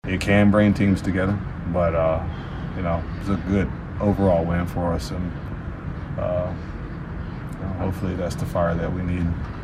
McCutchen said he didn’t think he was targeted, but he hopes the incident wakes the Pirates up for more than just last night’s game.